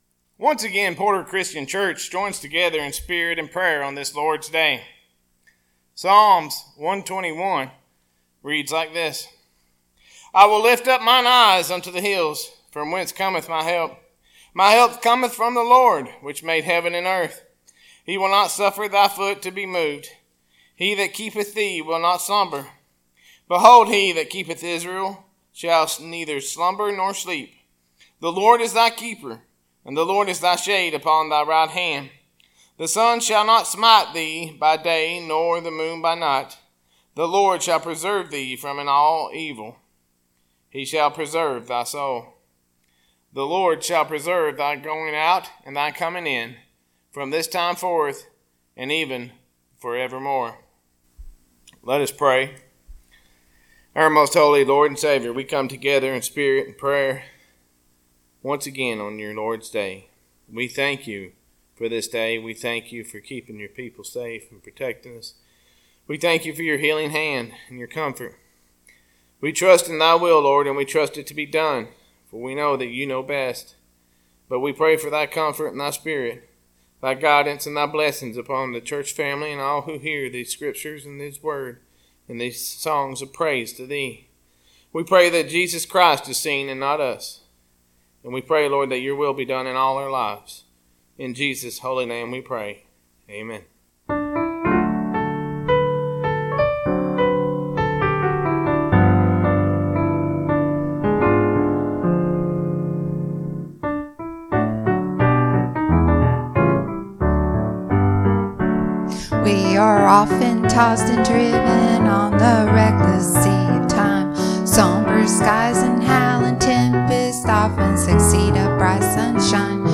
Sermons - Porter Christian Church